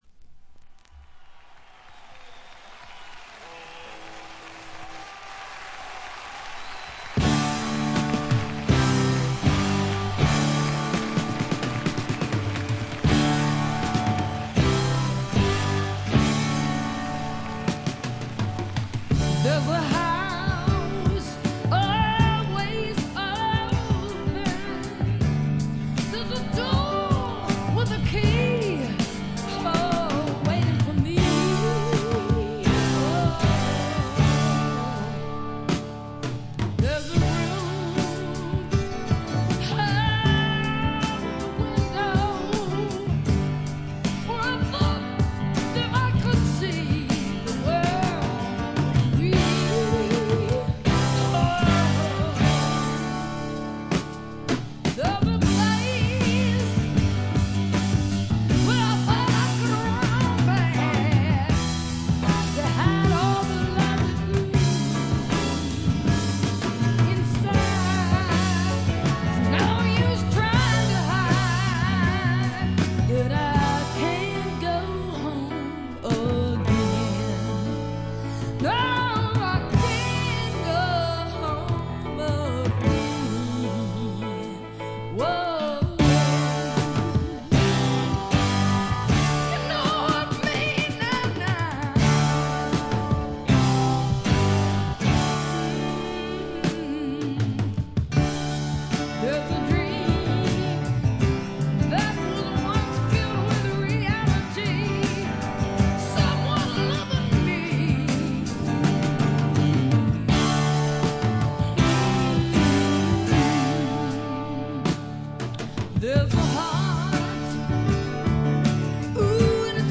部分試聴ですがチリプチ少々。
当時の流行であったスワンプと英国流ポップを合体させたような素晴らしい作品に仕上がっています。
試聴曲は現品からの取り込み音源です。
Recorded at Dick James Studio, London.